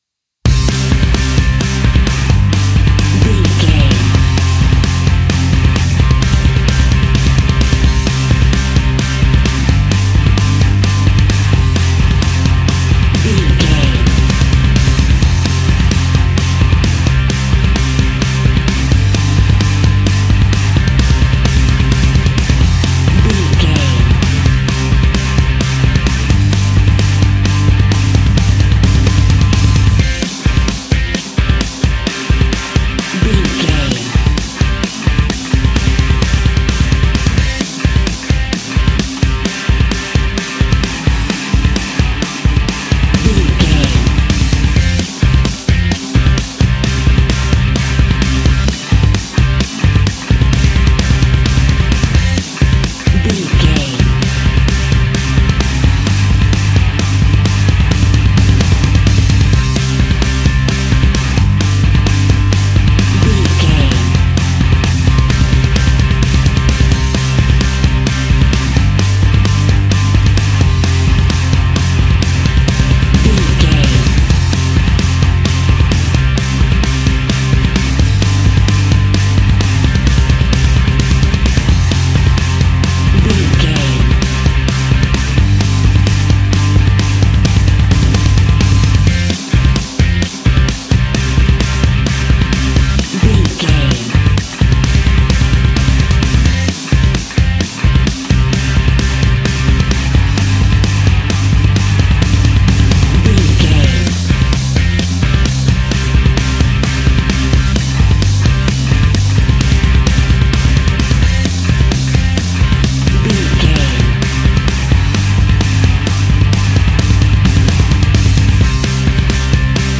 Ionian/Major
hard rock
punk metal
instrumentals
Rock Bass
heavy drums
distorted guitars
hammond organ